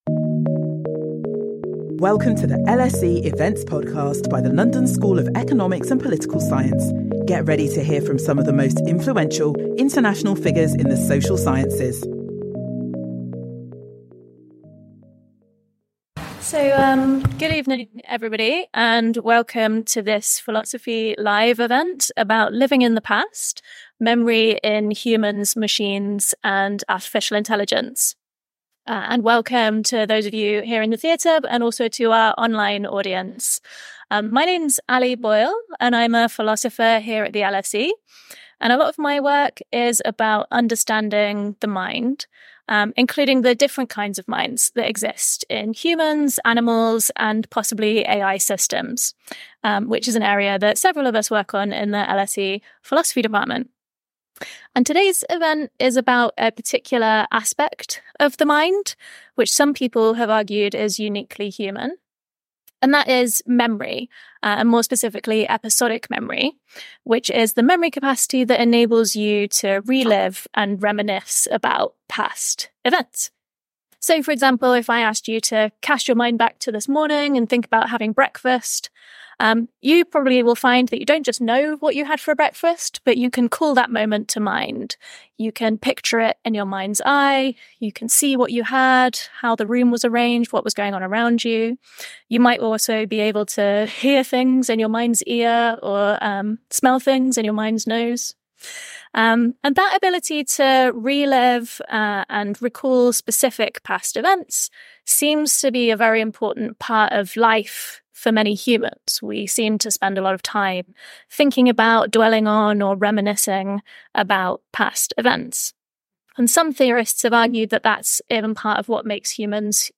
Our panel from philosophy, psychology and computer science explore episodic memory from its function – the ability to recall everyday events whether experienced or stated to its future in AI and more.